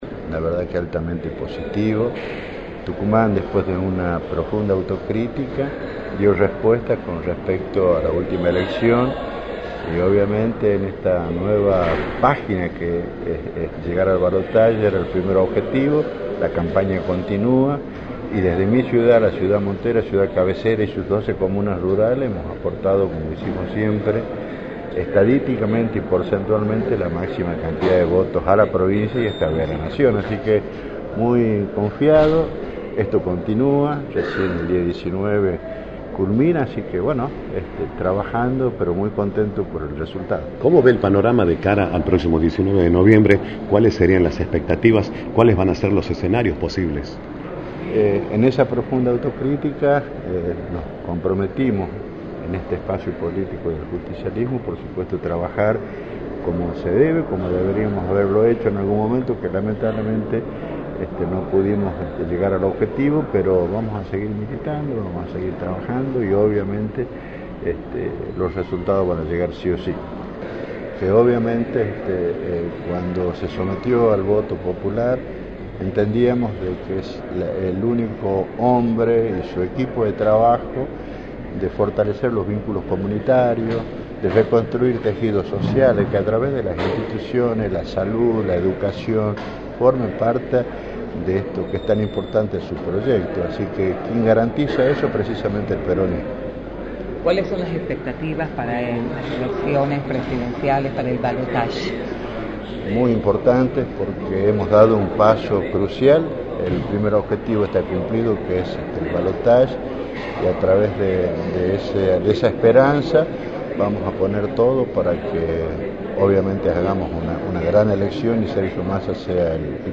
Francisco Pancho Serra, Intendente de Monteros, analizó en Radio del Plata Tucumán, por la 93.9, los resultados de las elecciones generales a nivel nacional